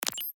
item_pickup.ogg